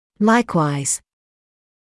[‘laɪkwaɪz][‘лайкуайз]подобно, таким же образом; также